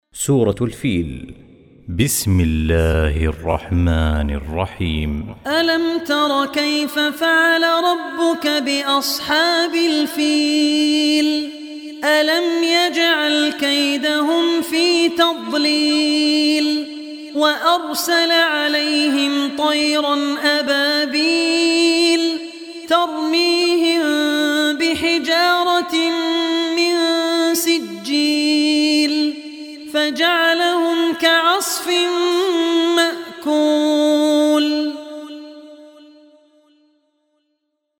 Surah Al Fil Recitation